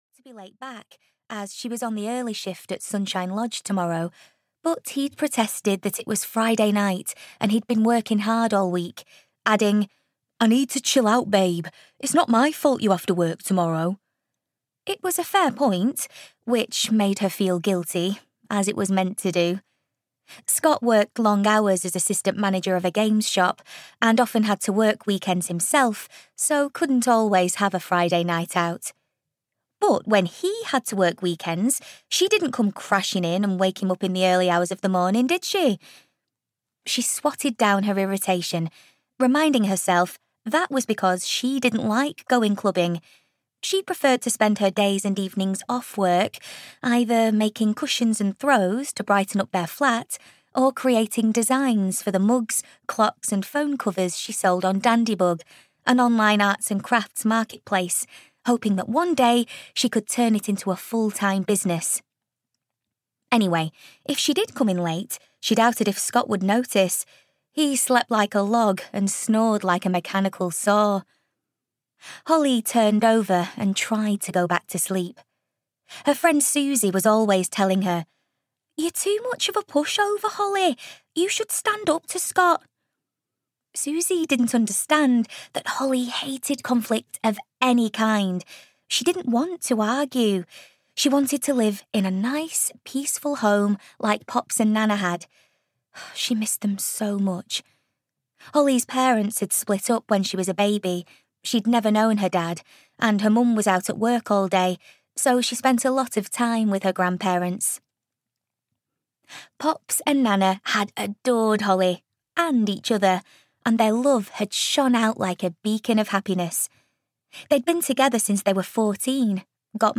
The Year of Starting Over (EN) audiokniha
Ukázka z knihy